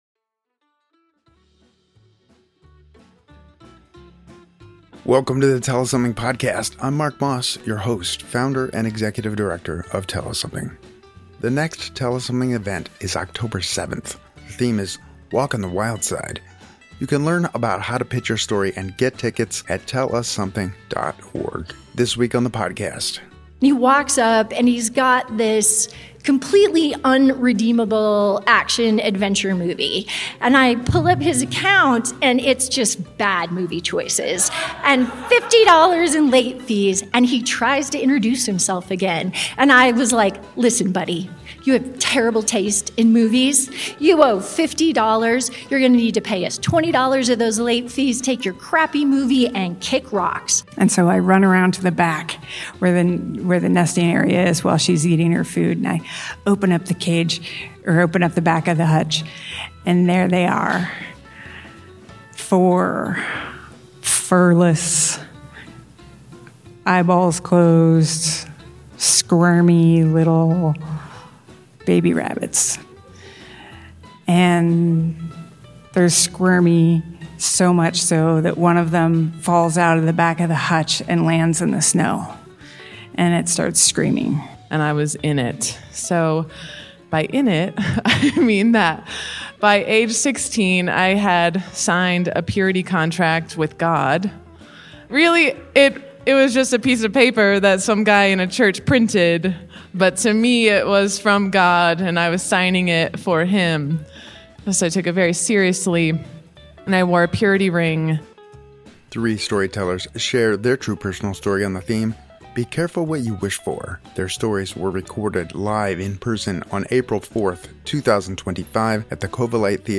Pat Williams joined us on the Tell Us something stage to share pieces of his extraordinary life with us. Each one delivered with that unmistakable wit, wisdom, and profound connection to humanity that he so loved.